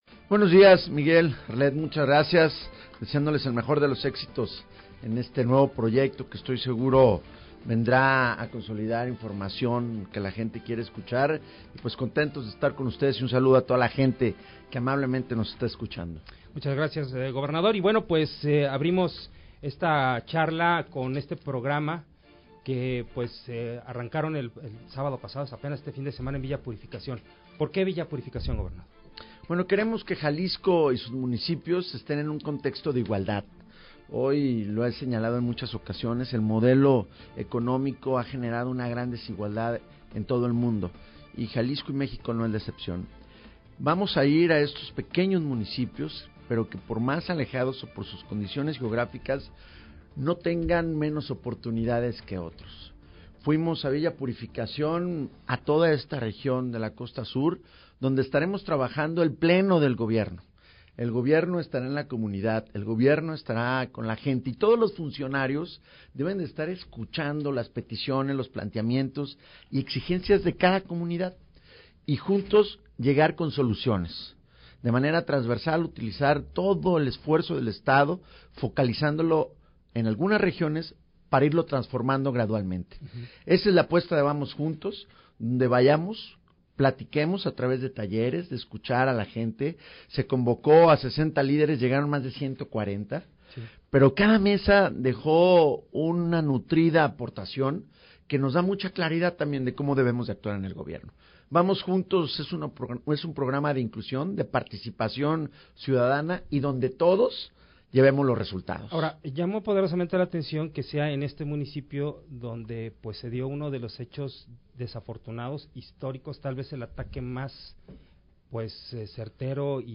ENTREVISTA 140915
El gobernador del Estado, Aristóteles Sandoval habló sobre el nuevo programa "Vamos Juntos" y temas de interés sobre su gestión